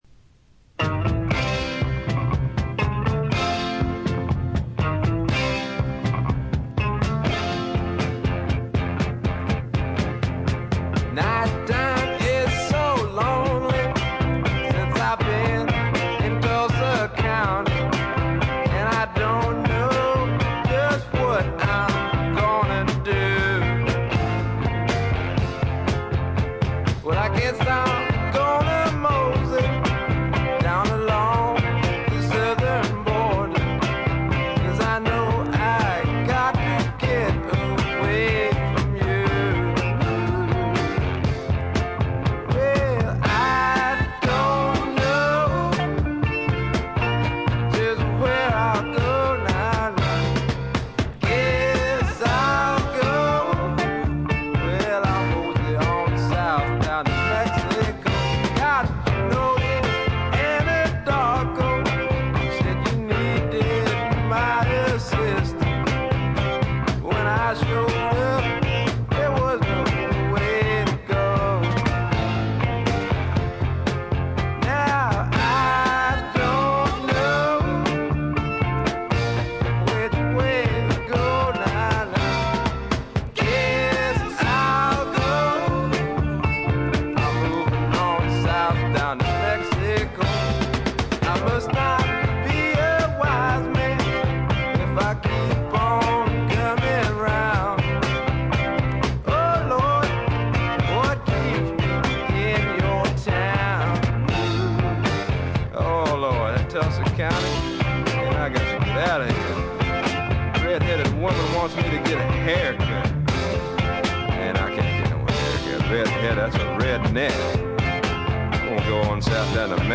軽微なチリプチ少し。
まさしくスワンプロックの真骨頂。
試聴曲は現品からの取り込み音源です。